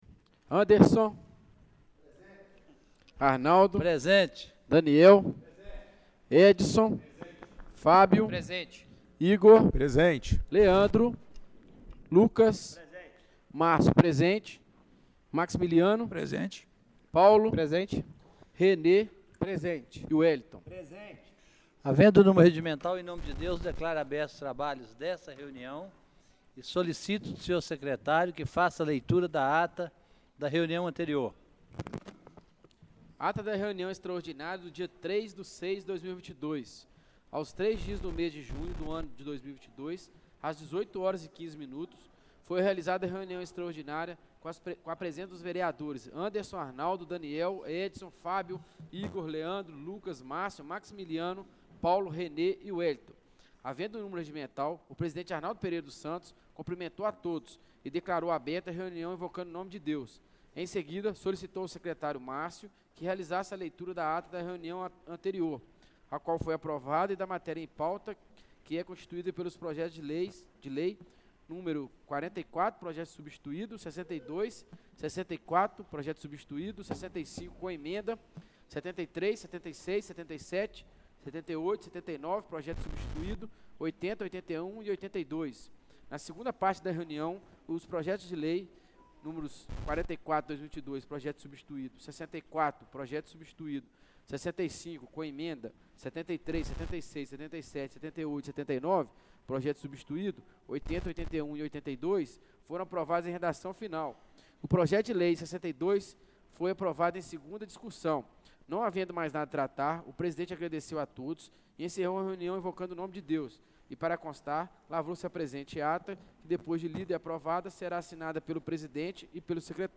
Reunião Ordinária do dia 06/06/2022